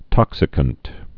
(tŏksĭ-kənt)